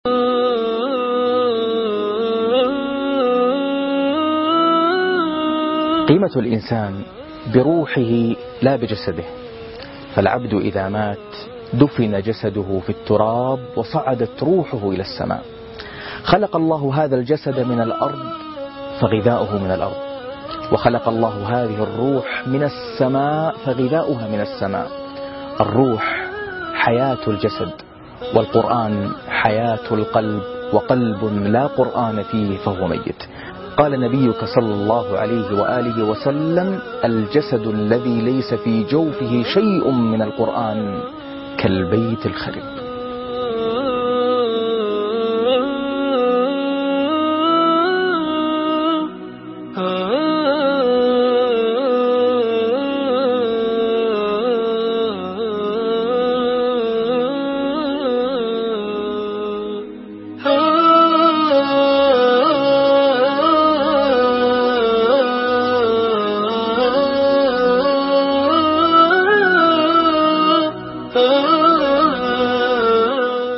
روح الحياة (5-7-2014) تغريدات قرآنية - القاريء ناصر القطامي